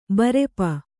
♪ barepa